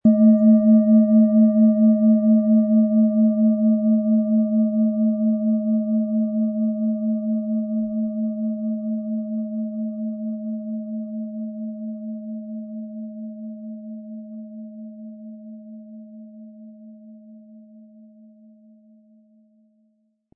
Im Audio-Player - Jetzt reinhören hören Sie genau den Original-Klang der angebotenen Schale. Wir haben versucht den Ton so authentisch wie machbar aufzunehmen, damit Sie gut wahrnehmen können, wie die Klangschale klingen wird.
Ein die Schale gut klingend lassender Schlegel liegt kostenfrei bei, er lässt die Planetenklangschale Alphawelle harmonisch und angenehm ertönen.
SchalenformBihar
MaterialBronze